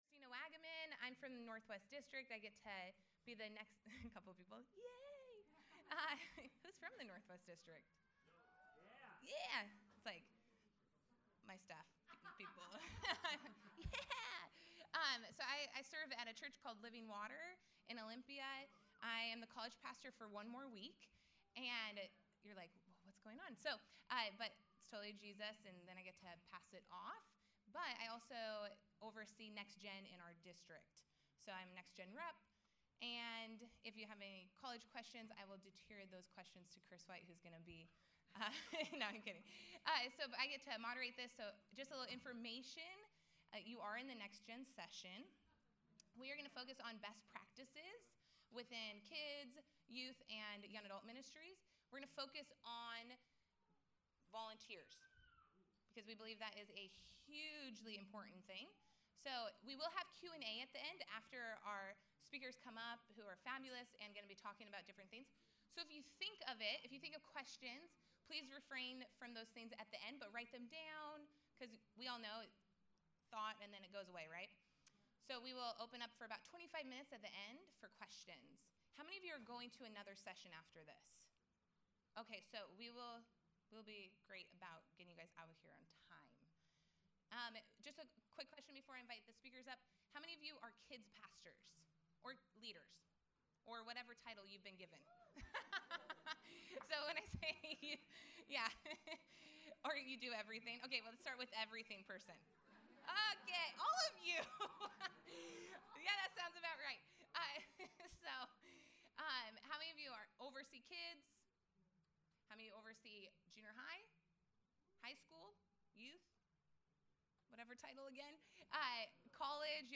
Listen to all of the Foursquare Connection 2017 workshops
FS17-TrainingSession-NextGenMinistry-2.mp3